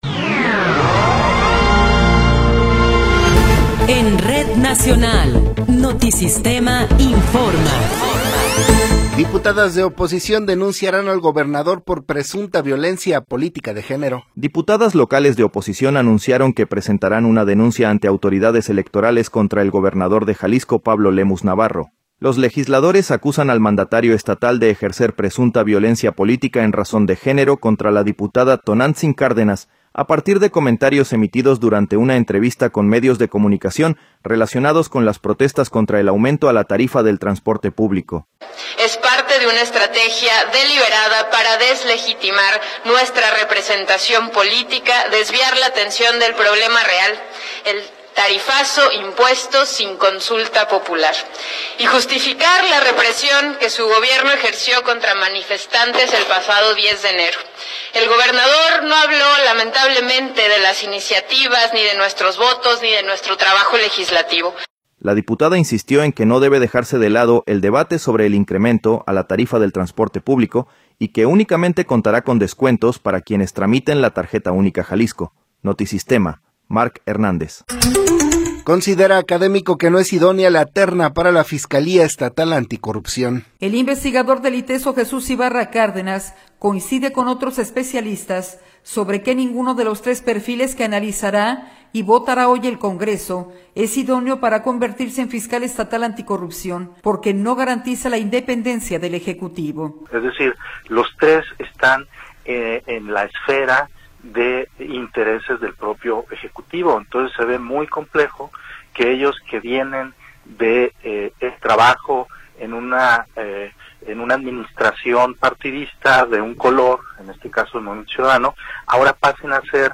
Noticiero 12 hrs. – 20 de Enero de 2026